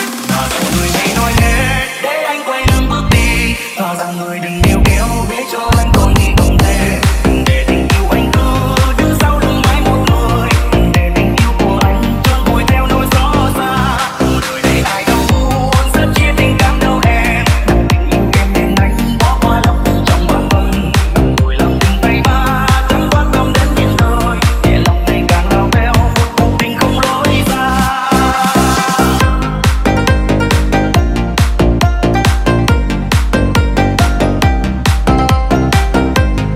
Nhạc Remix.